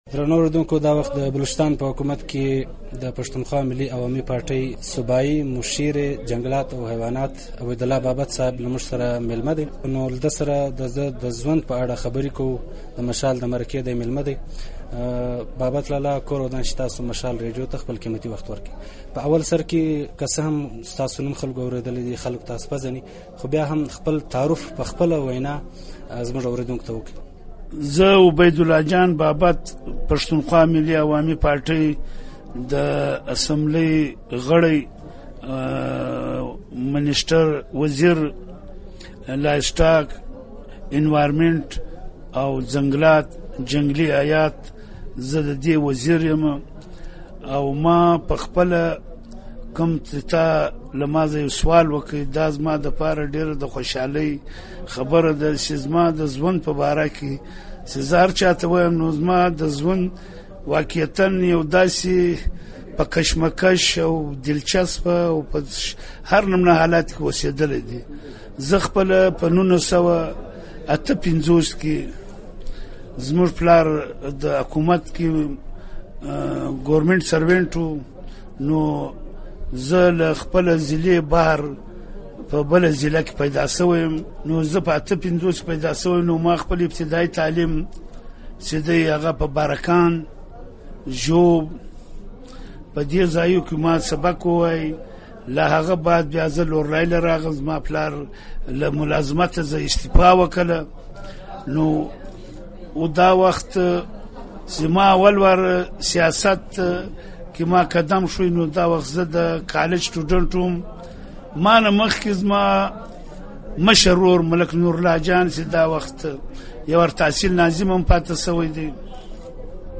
د پښتون ملتپالي او واکمن ګوند، پښتونخوا ملي عوامي پارټۍ یو مشر او په صوبایي حکومت کې د ځنګلاتو، حېواناتو او چاپېریال څانګې سنبالوونکی عبیدالله جان بابت سره د مشال مرکه